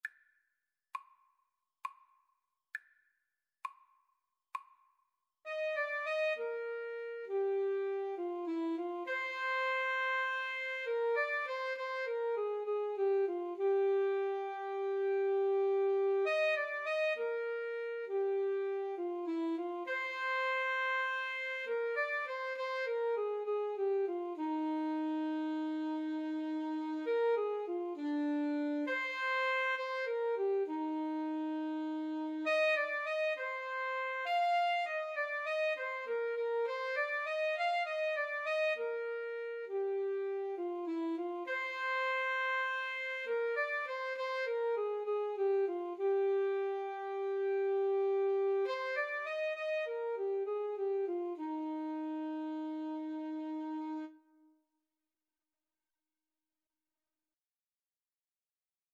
Alto SaxophoneTenor Saxophone
Moderato